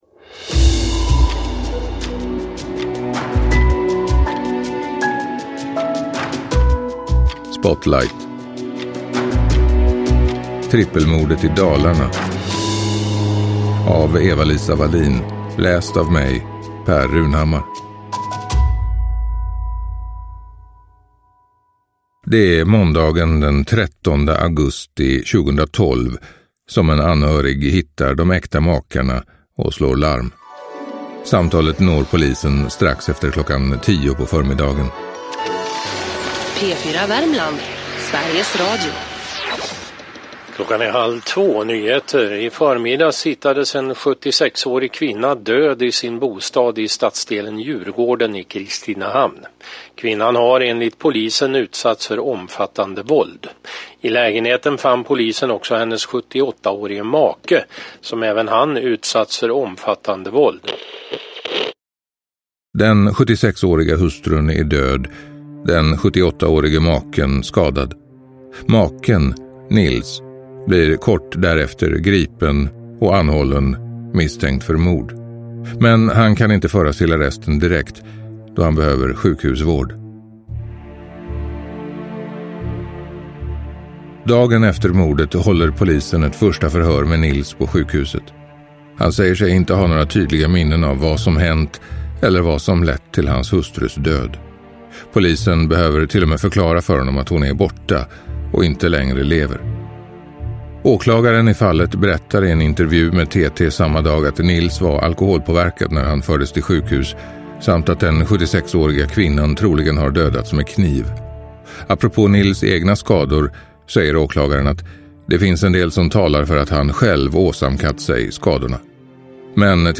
Trippelmordet i Dalarna – Ljudbok – Laddas ner